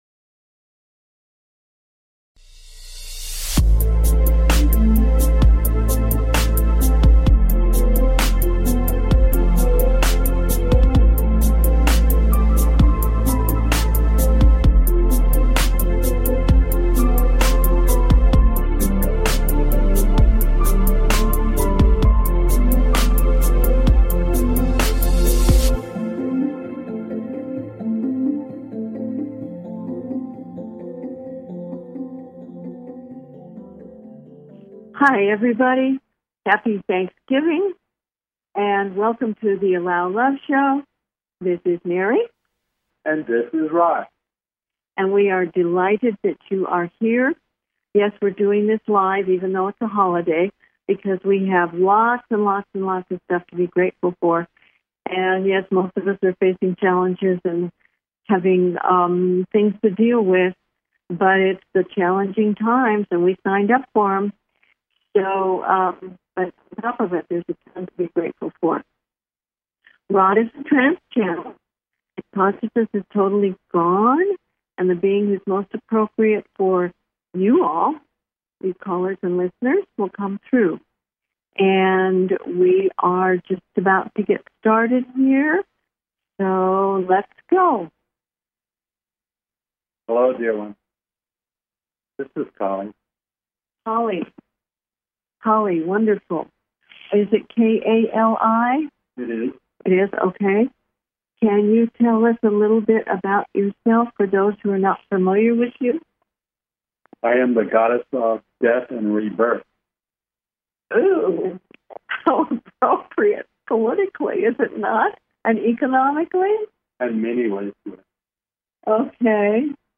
Talk Show Episode
Their purpose is to provide answers to callers’ questions and to facilitate advice as callers request.